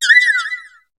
Cri de Fluvetin dans Pokémon HOME.